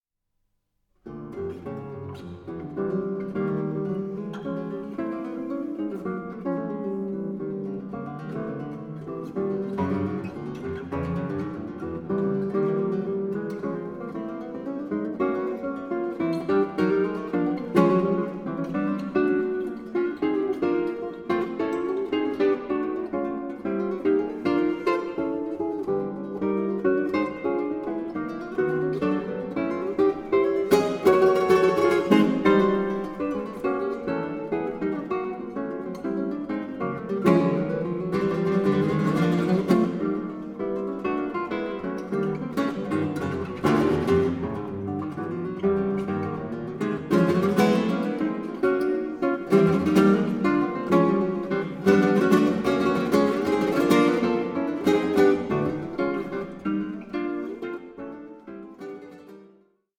SOLO GUITAR MASTERPIECE
Guitarist
virtuosic